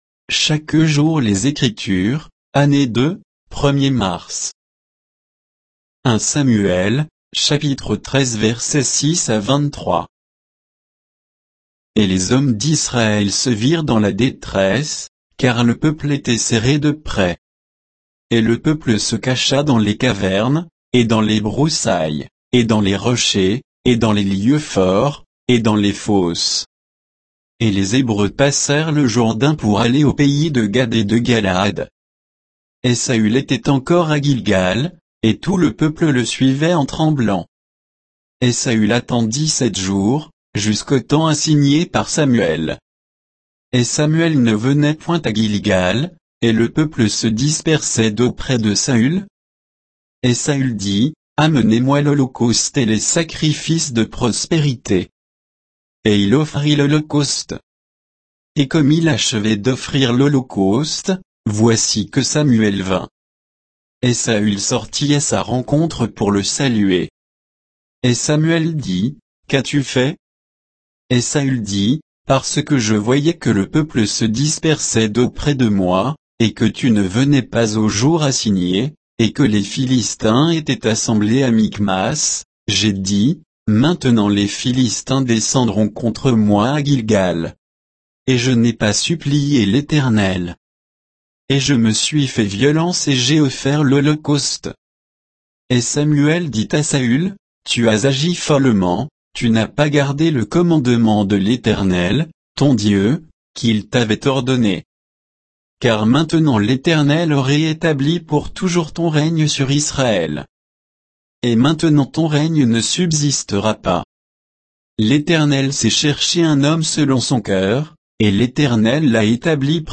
Méditation quoditienne de Chaque jour les Écritures sur 1 Samuel 13